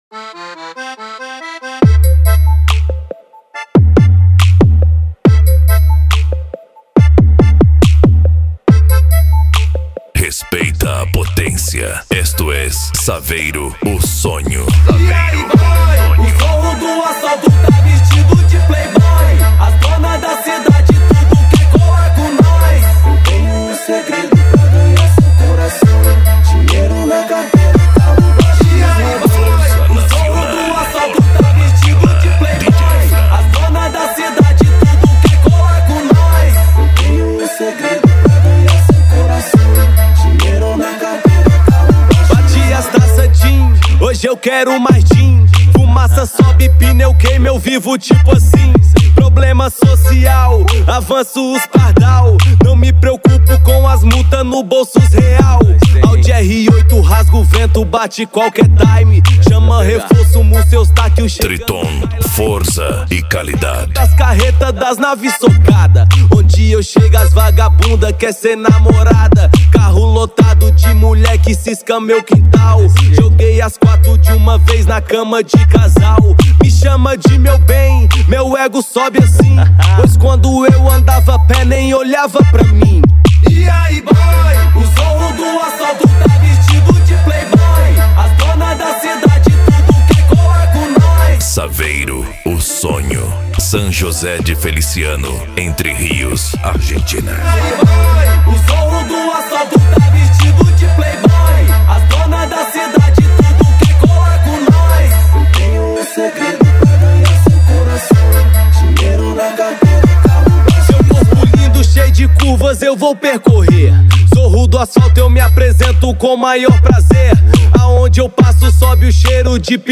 Bass
Funk
SERTANEJO